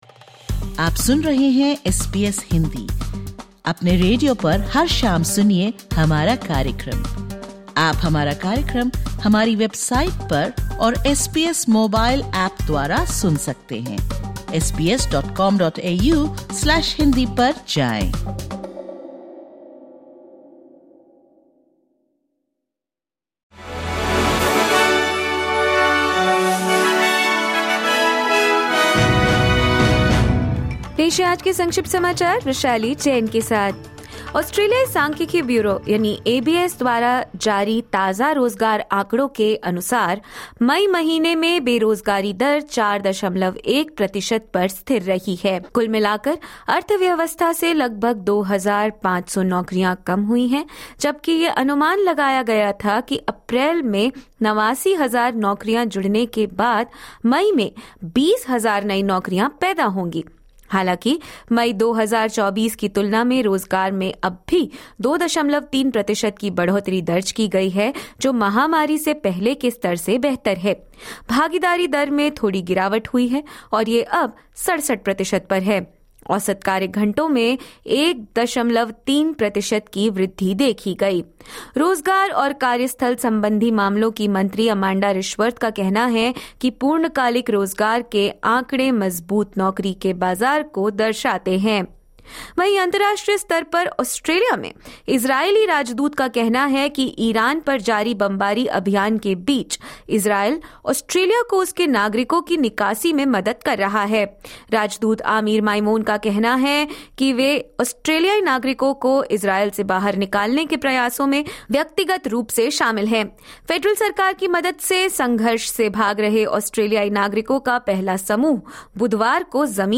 Listen to the top News of 19/06/2025 from Australia in Hindi.